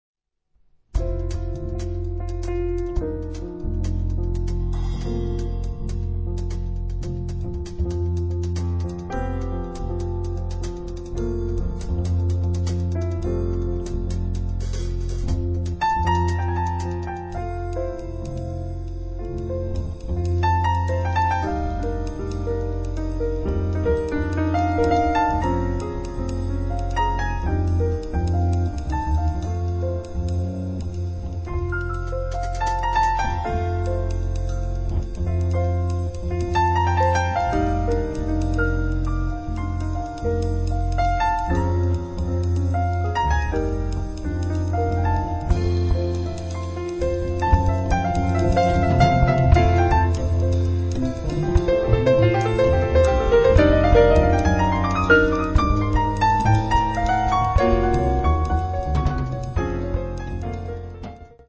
bass
drums
piano
trombone
trumpet
soprano sax